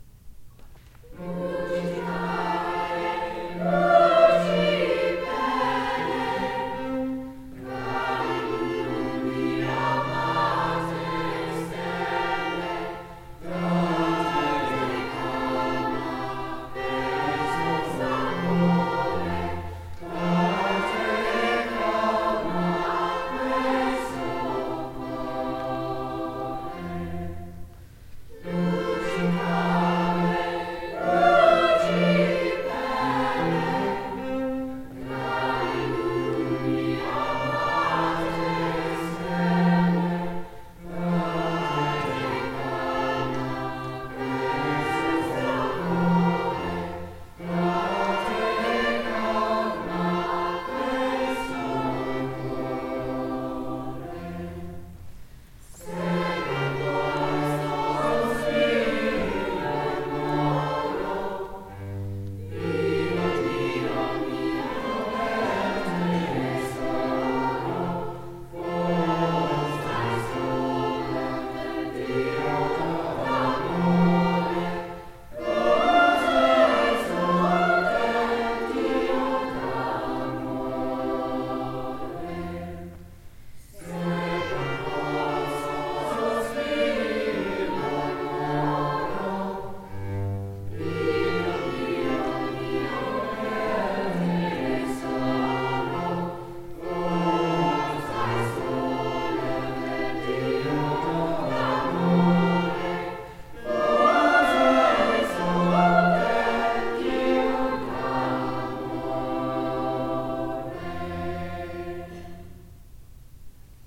Chamber, Choral & Orchestral Music
2:00 PM on August 13, 2017, St. Mary Magdalene
Chorus